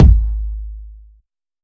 000 Kick LazerDice 6.wav